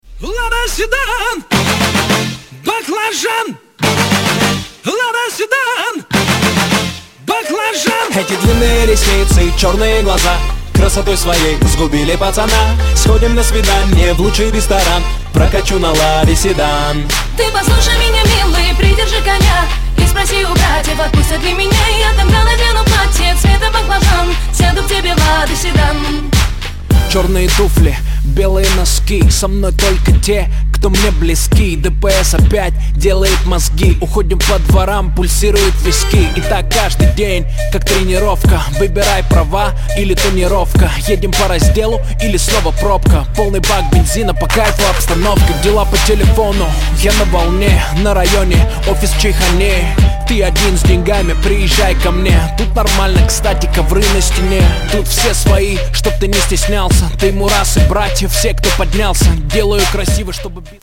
• Качество: 128, Stereo
рэп
Хип-хоп
веселые